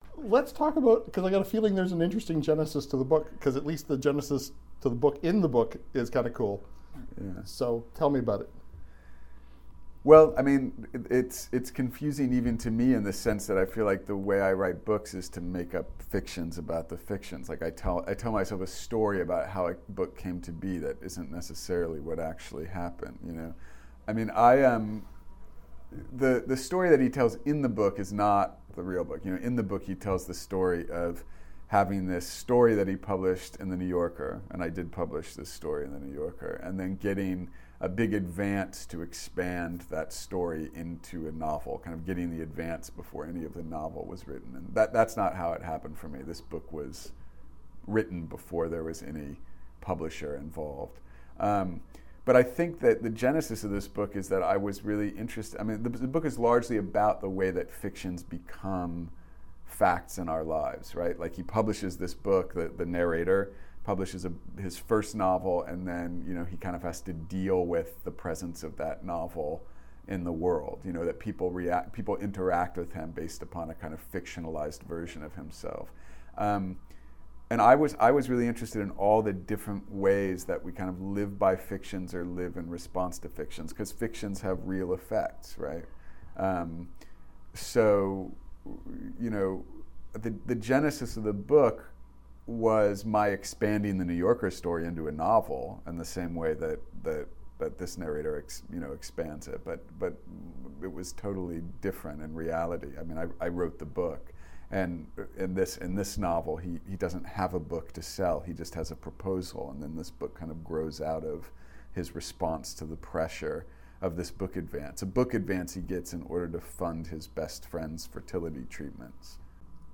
Recording Location: Toronto
Type: Interview